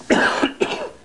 Cough Sound Effect
Download a high-quality cough sound effect.
cough-3.mp3